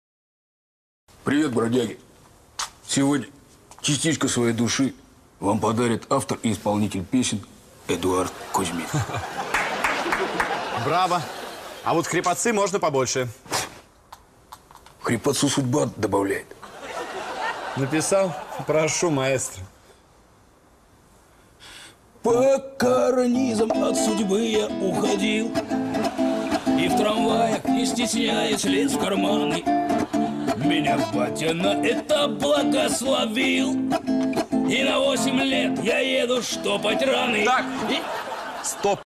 Дворовые под гитару
шансон на гитаре